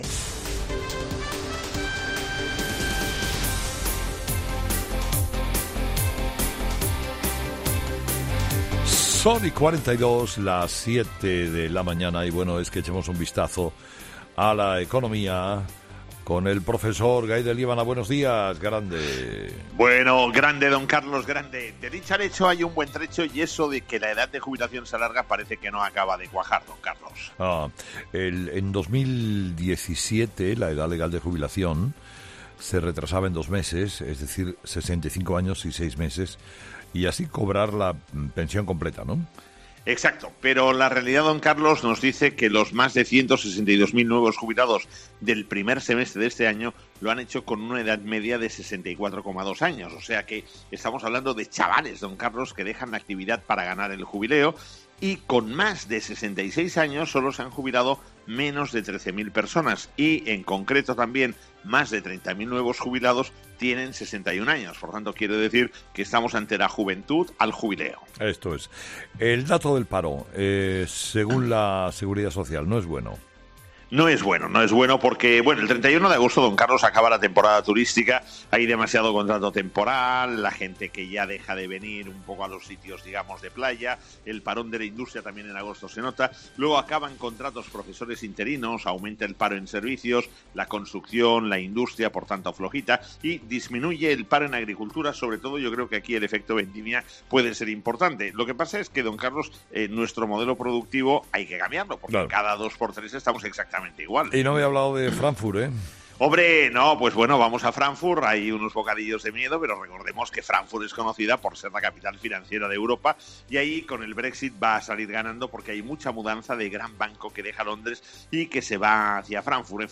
Todas las mañanas a las 7.40 horas, la actualidad económica con el profesor Gay de Liébana.